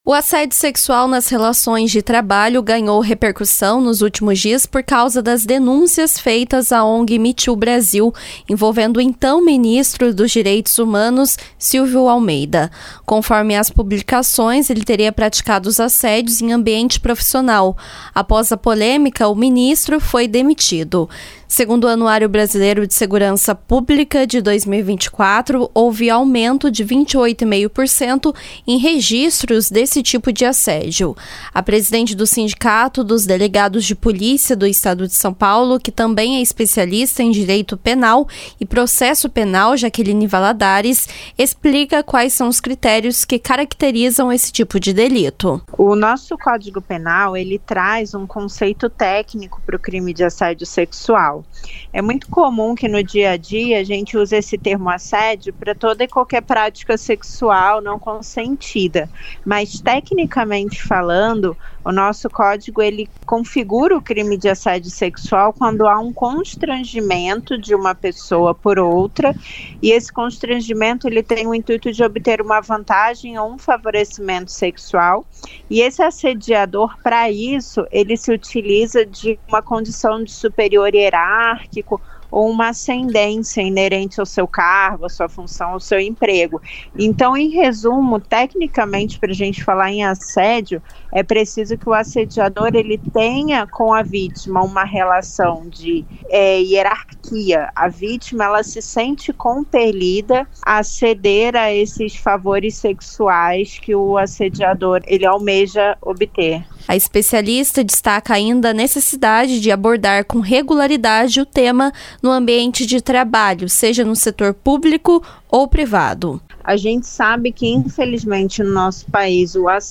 Editorial veiculado da Rede Aparecida de Rádio e Signis Brasil, dia 13 de setembro de 2024